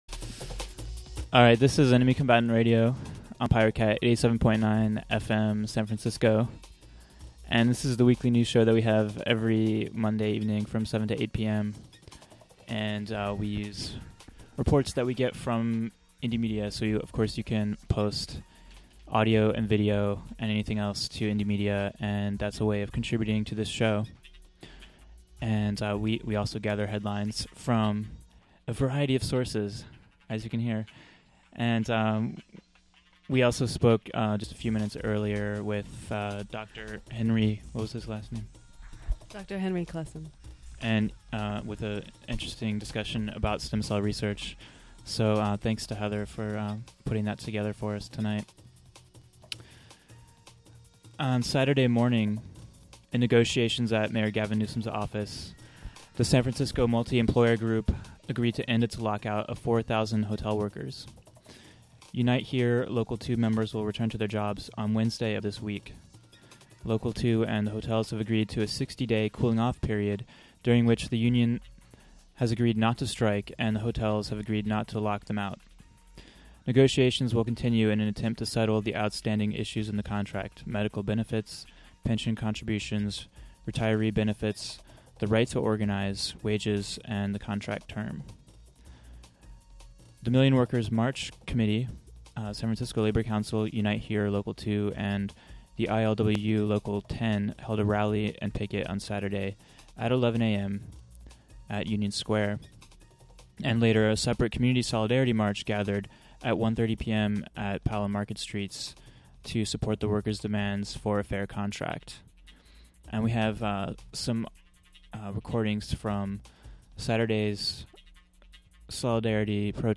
Segment on community solidarity... featuring sounds from the last flying picket and the community support action on 11/20